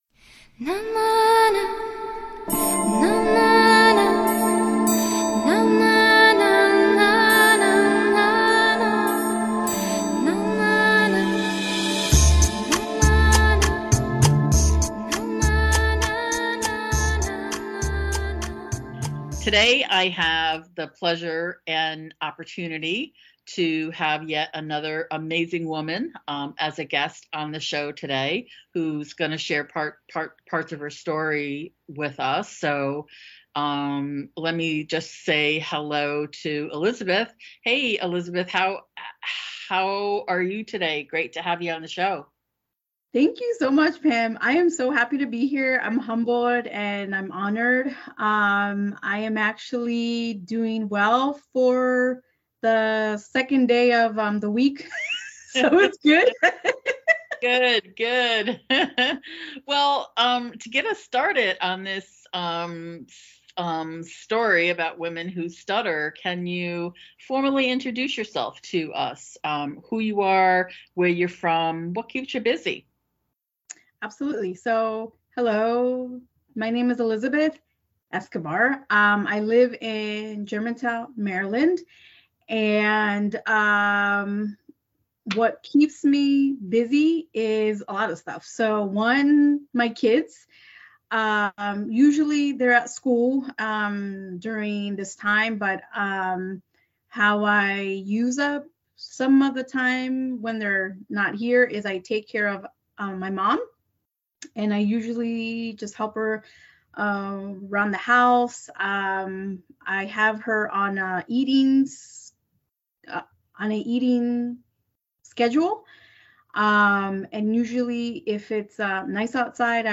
who speaks and stutters in three languages: English, Spanish and Arabic.